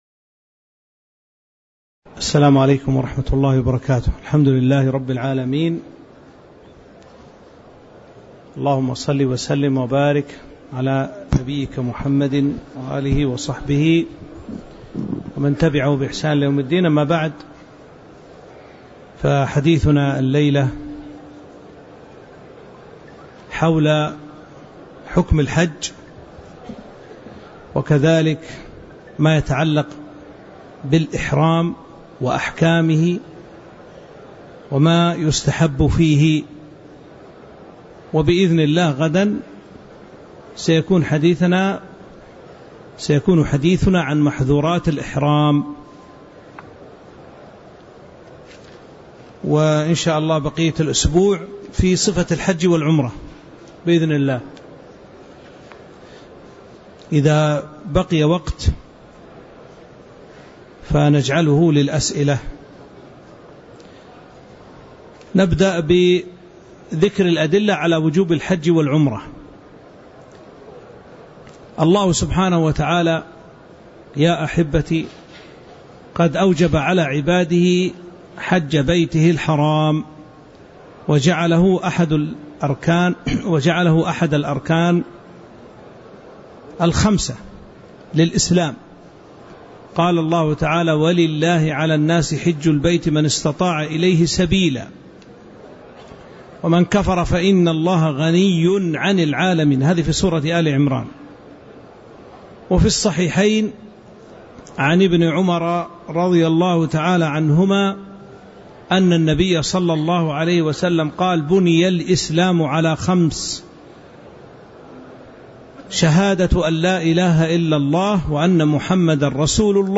تاريخ النشر ٢٥ ذو القعدة ١٤٤٥ هـ المكان: المسجد النبوي الشيخ